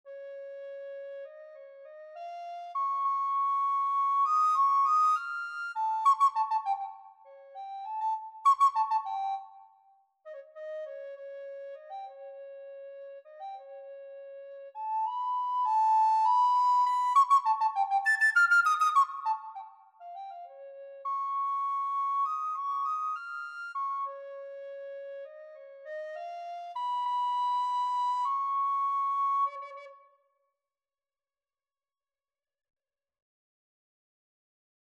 The task for this project was to compose nine pieces for woodwind instruments for nine different scales.
recorder-audio.mp3